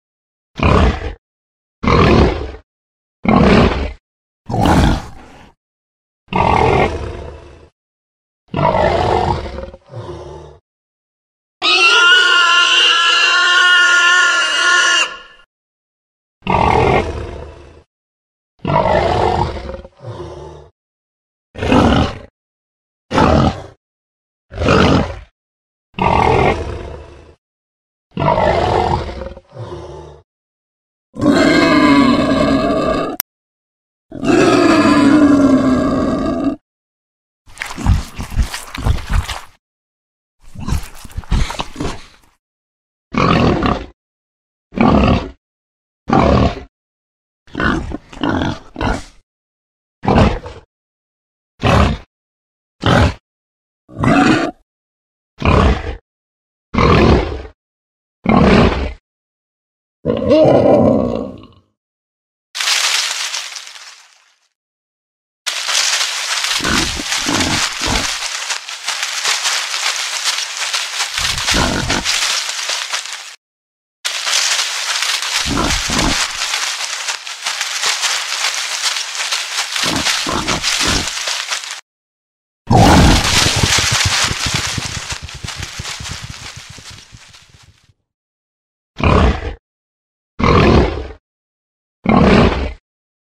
Звуки мутантов
Здесь вы найдете устрашающие рыки, крики и звуки атаки всех мутантов из вселенной S.T.A.L.K.E.R. Скачивайте высококачественные аудиофайлы в формате mp3 бесплатно для монтажа видео, установки на телефон или использования в других целях.
Кабан мутант